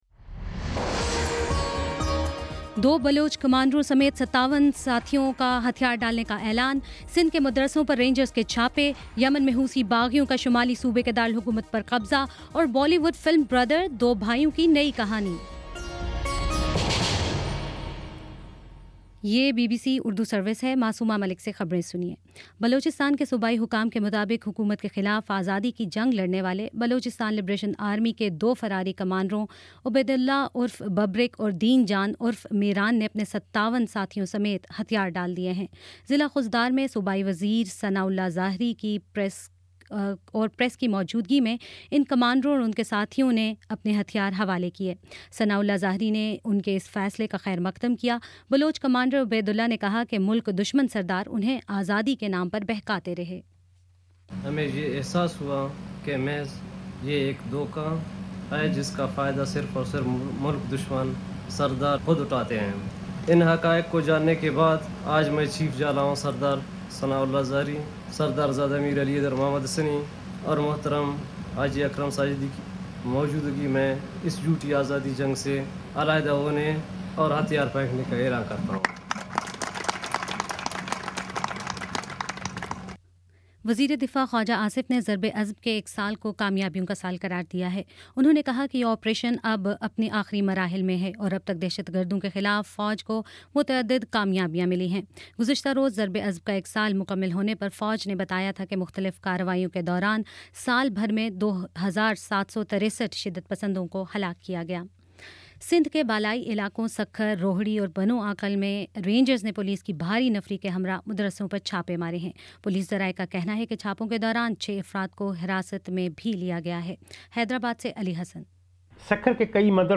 جون 14: شام پانچ بجے کا نیوز بُلیٹن